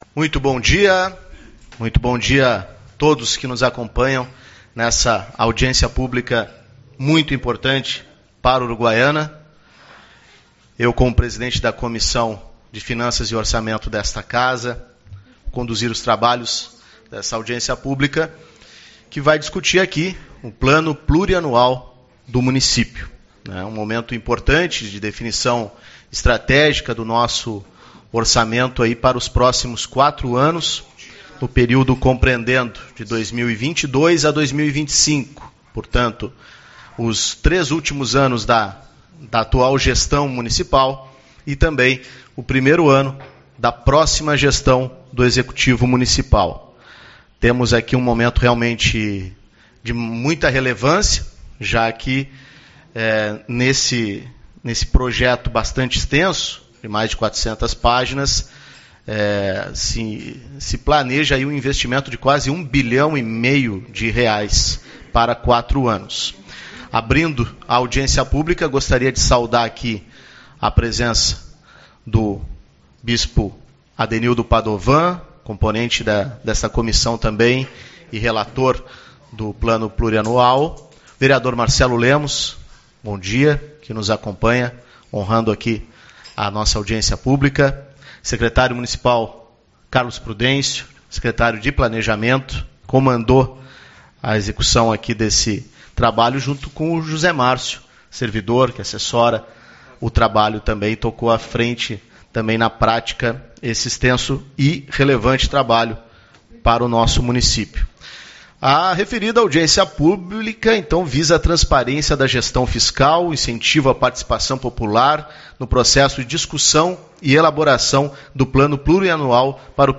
20/08 - Audiência Pública-PPA